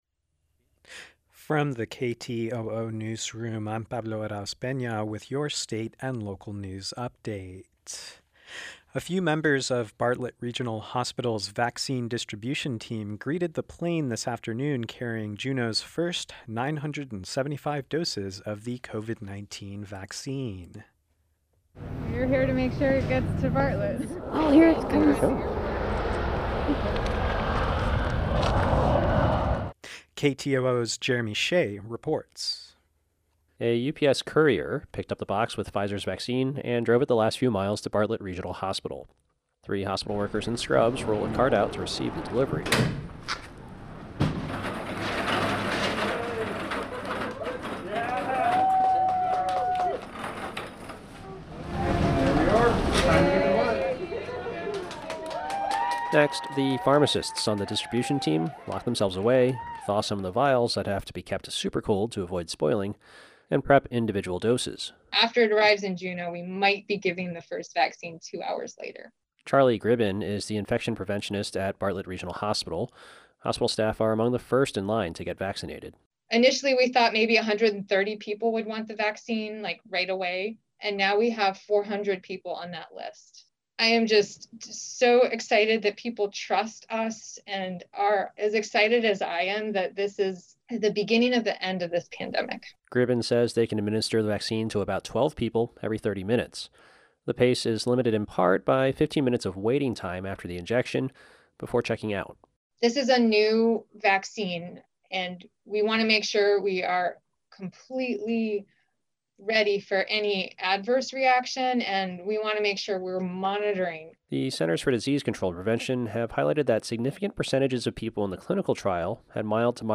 Newscast – Tuesday, Dec. 15, 2020